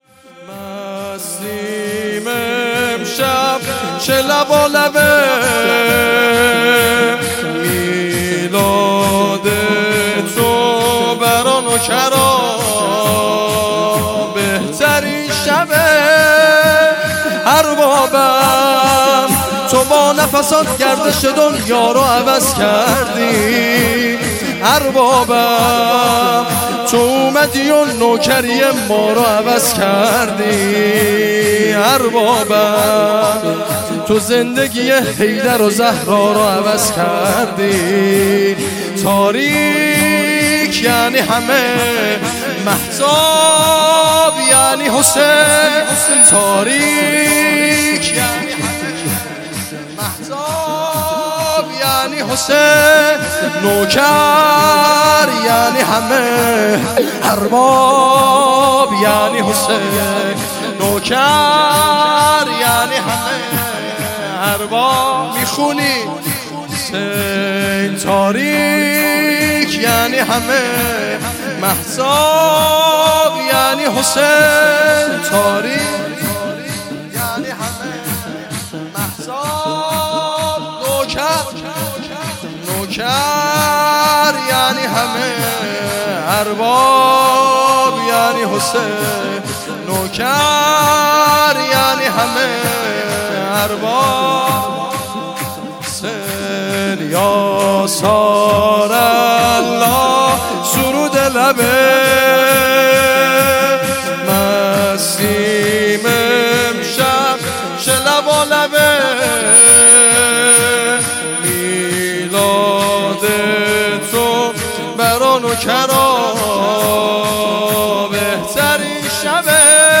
شور
جشن میلاد سرداران کربلا علیهم السلام